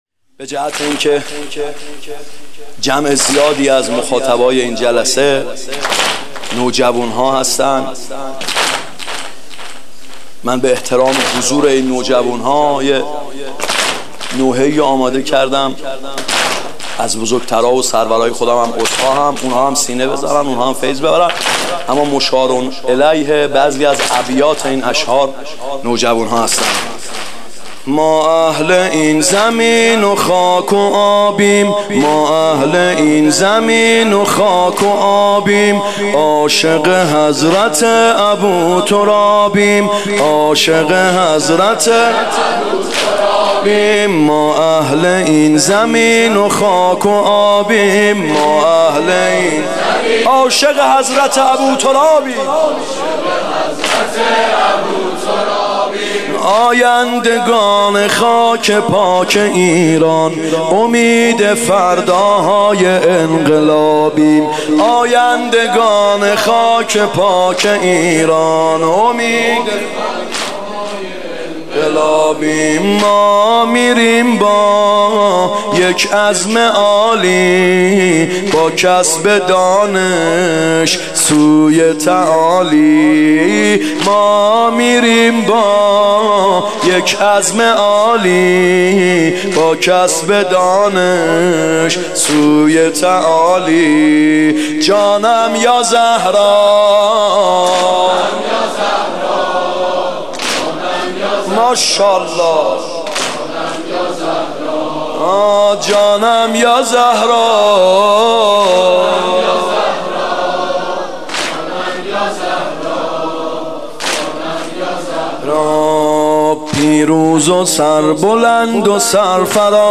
فاطمیه اول هیات یامهدی عج